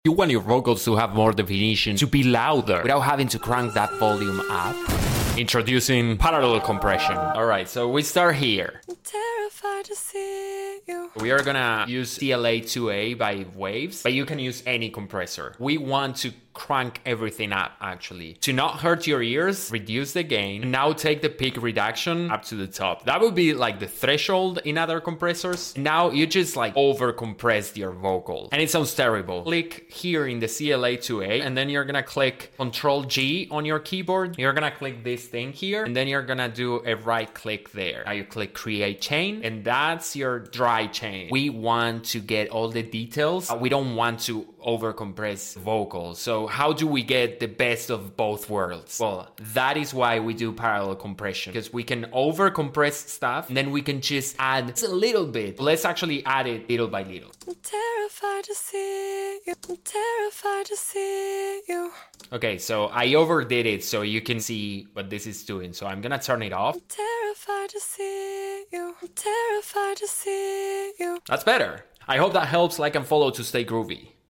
(No, not that Messi ⚽) We're starting with a simple beat, but by adding some off-kilter claps and hi-hats, we'll create a more organic and interesting sound. 🎶 Here's how: Add claps, but make them slightly off-time for a cool flam effect. For extra warmth and groove, shift your hi-hats a tiny bit off the beat, depending on your song's genre.